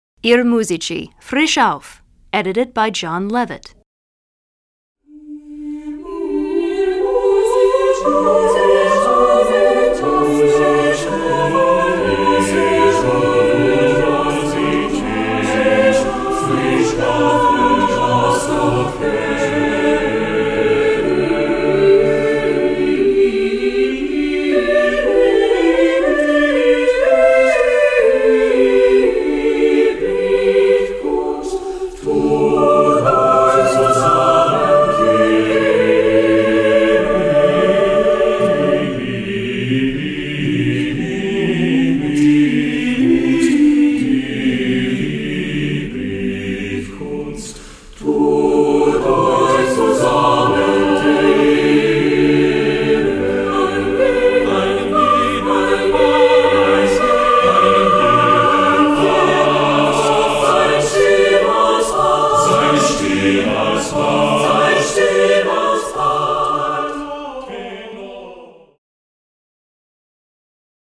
Voicing: SSATBB